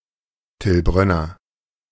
Till Brönner (German: [tɪl ˈbʁœnɐ]